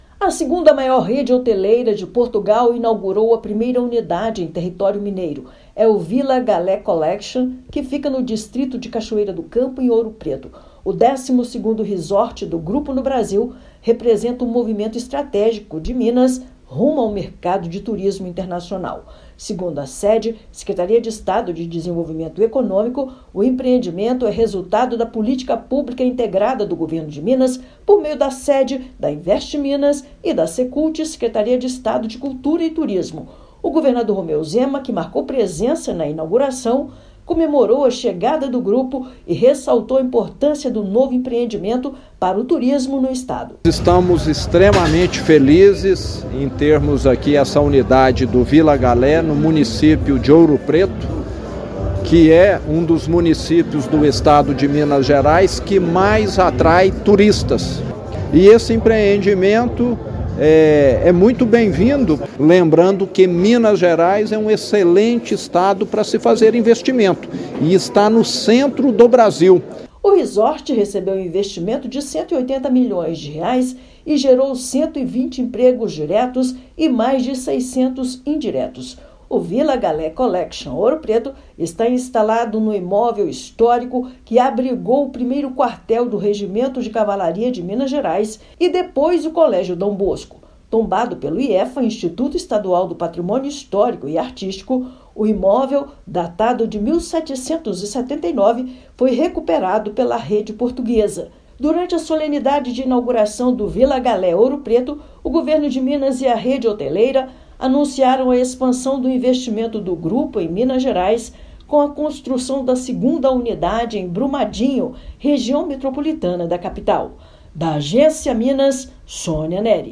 Instalação da primeira unidade da rede no estado é marco estratégico que fortalece a presença de Minas no cenário global. Durante solenidade, grupo anunciou a construção de um segundo resort em Minas, na cidade de Brumadinho. Ouça matéria de rádio.